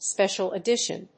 音節spècial edítion